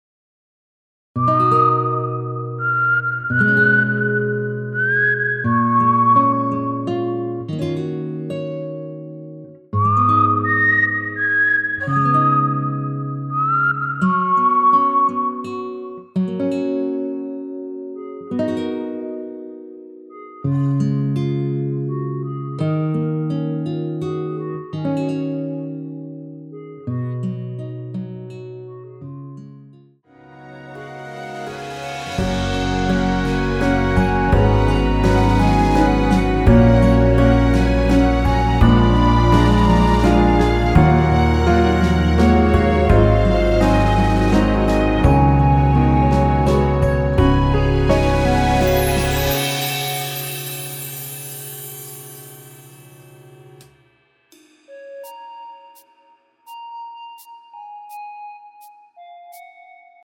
무반주 구간 들어가는 부분과 박자 맞출수 있게 쉐이커로 박자 넣어 놓았습니다.(미리듣기 확인)
원키에서(-2)내린 멜로디 포함된 MR입니다.(미리듣기 확인)
F#
앞부분30초, 뒷부분30초씩 편집해서 올려 드리고 있습니다.
중간에 음이 끈어지고 다시 나오는 이유는